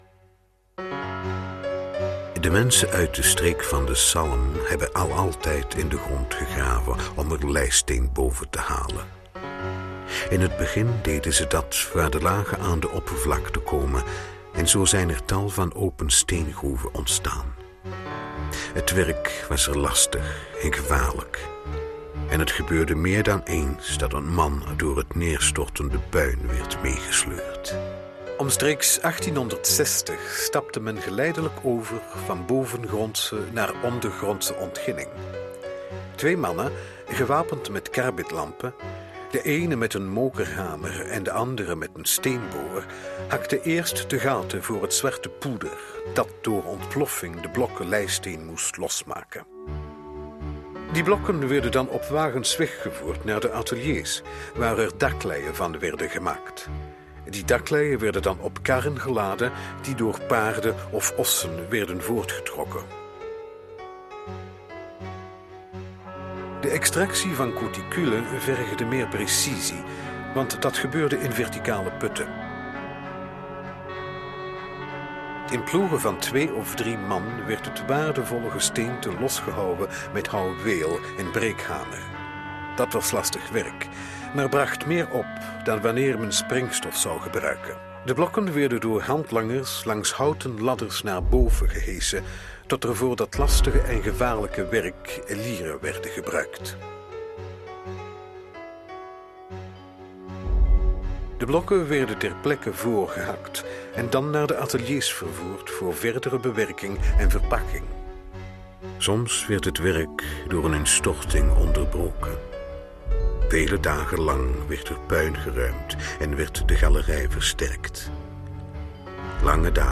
Commentaar bij de maquette